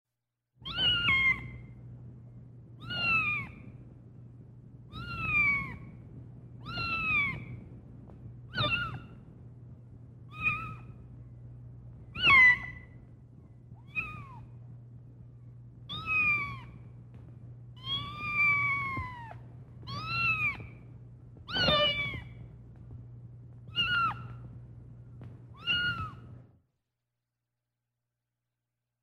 Kitten meowing ringtone free download
Animals sounds